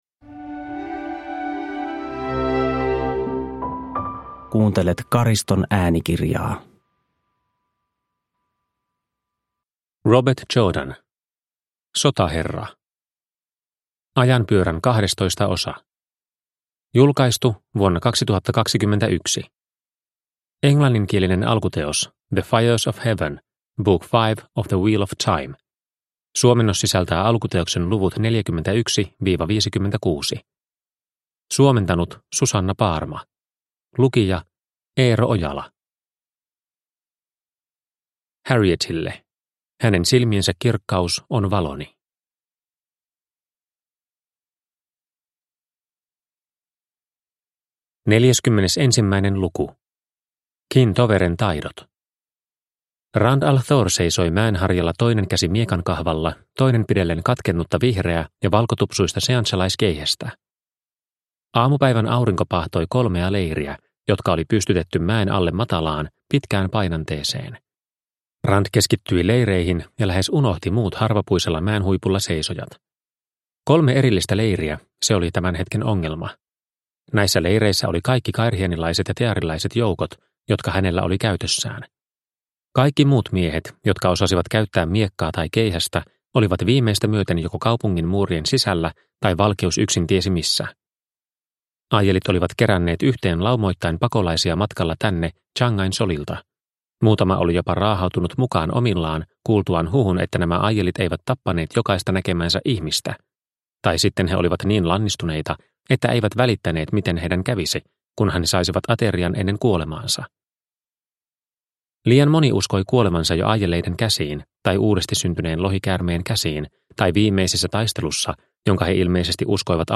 Sotaherra – Ljudbok – Laddas ner